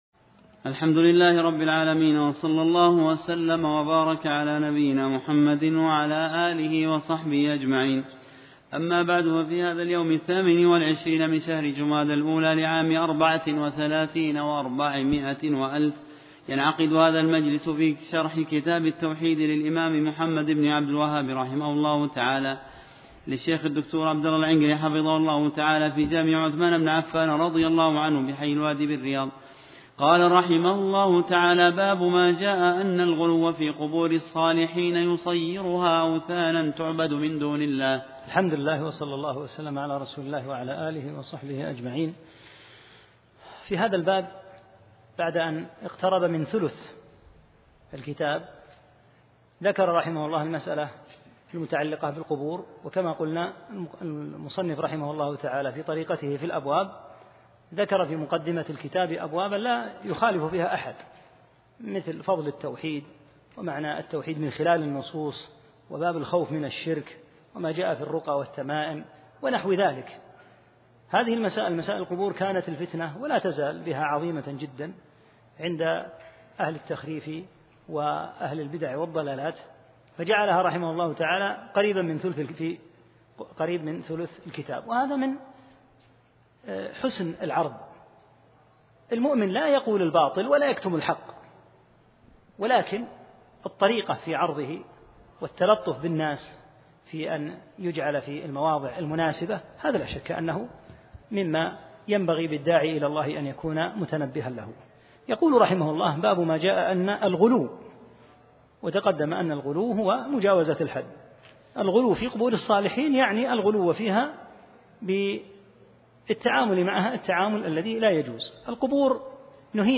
8- الدرس الثامن